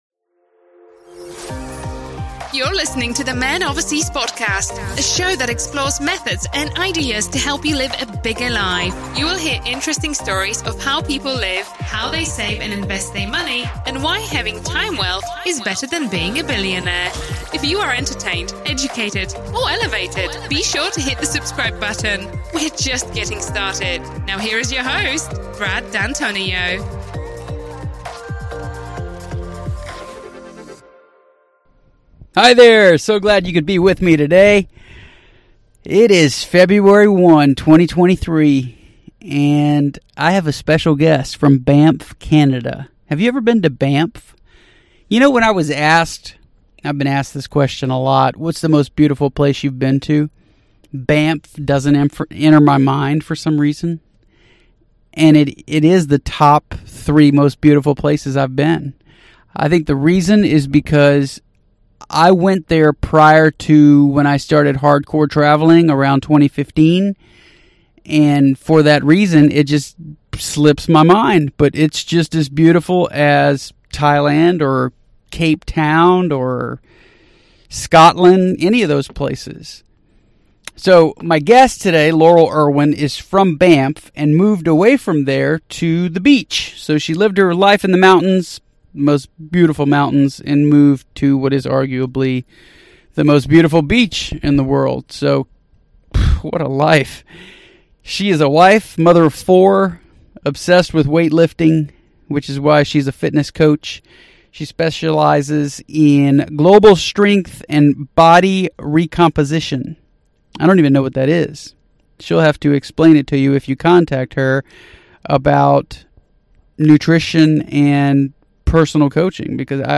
It felt like healthy debate, at times, which is something I realized in our back & forth that we’re lacking in this country.